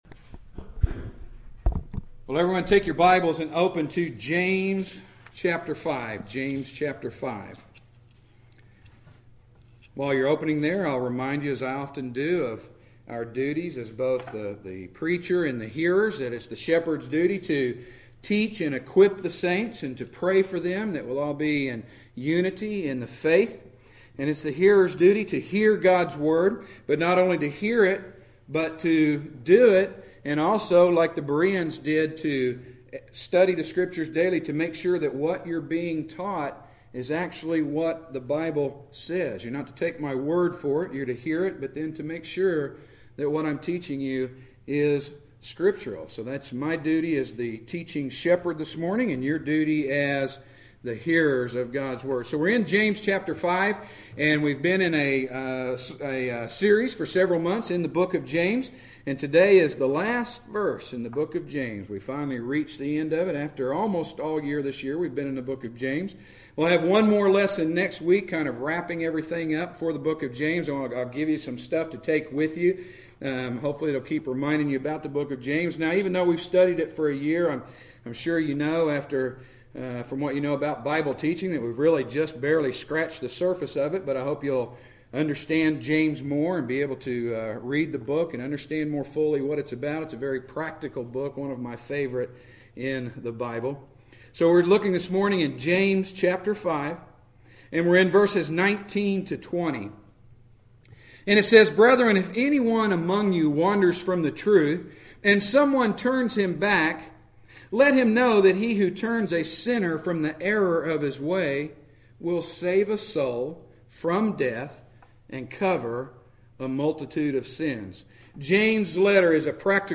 Preached at Straightway Bible Church on Sept 22, 2013.